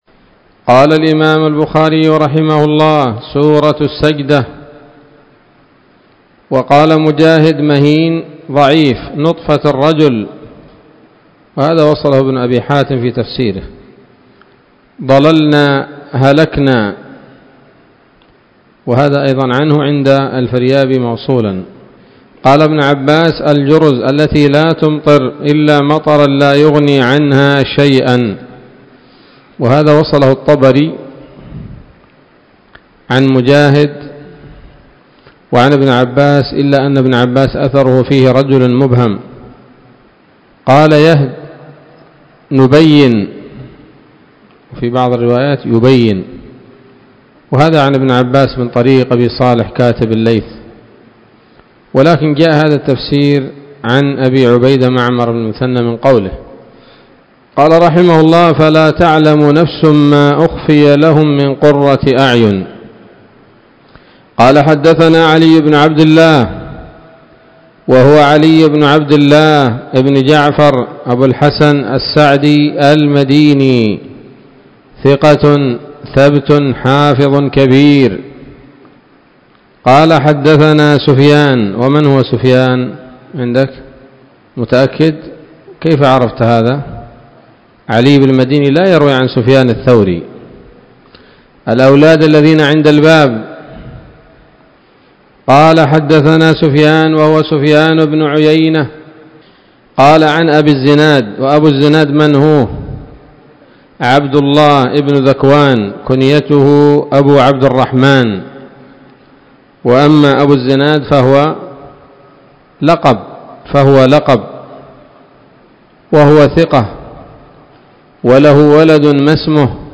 الدرس المئتان من كتاب التفسير من صحيح الإمام البخاري